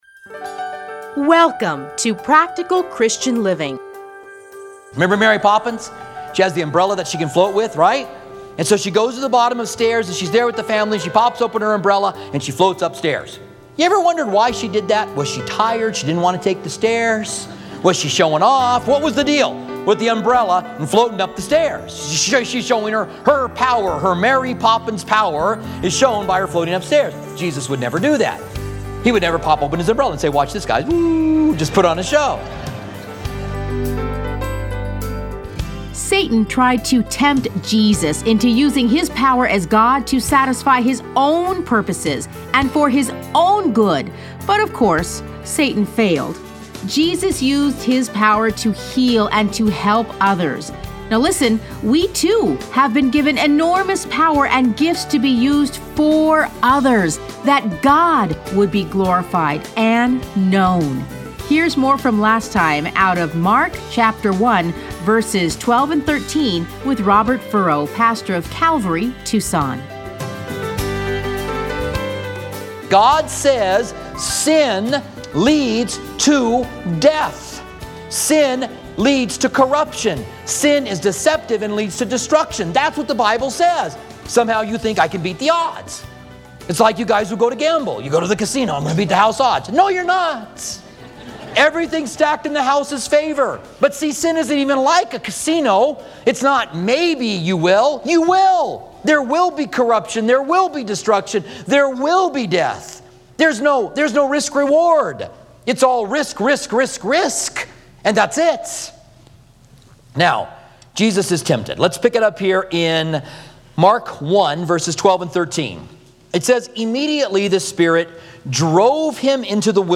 Listen to a teaching from Mark 1:12-13.